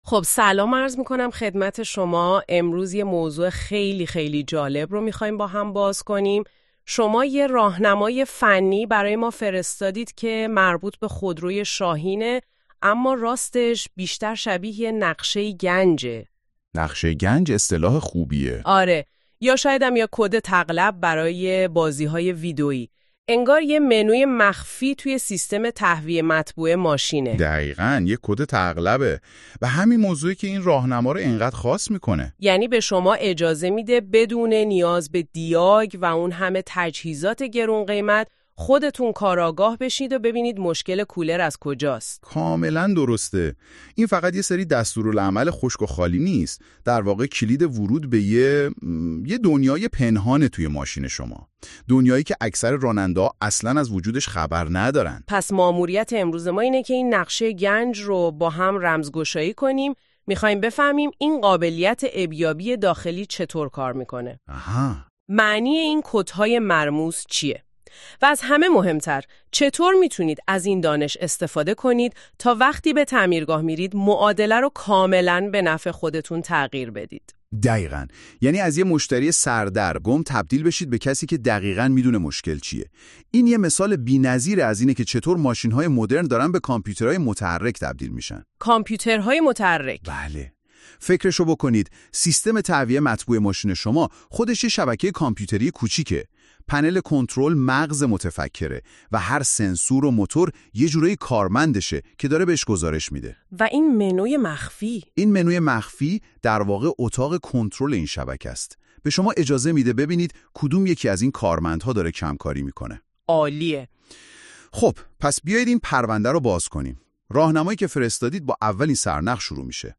پادکست مقاله عیب یابی کولر شاهین بدون دیاگ (خود عیب یاب)